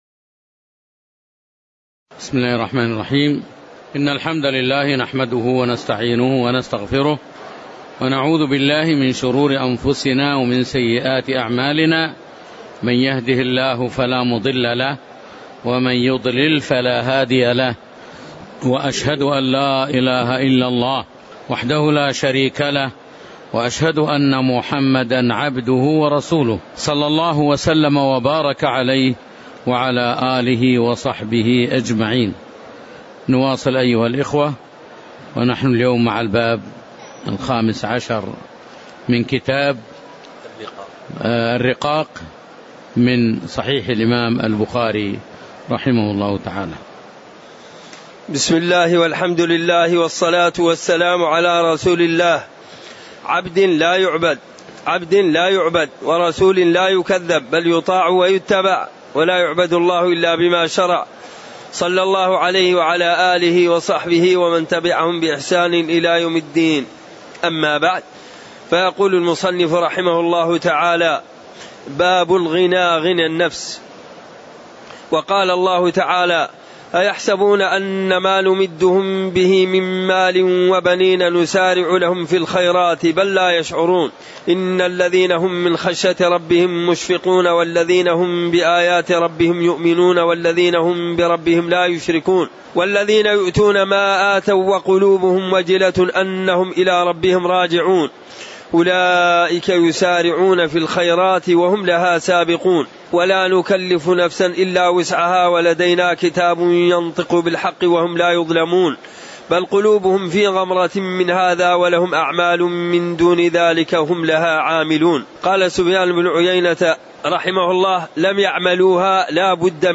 تاريخ النشر ٧ رمضان ١٤٣٩ هـ المكان: المسجد النبوي الشيخ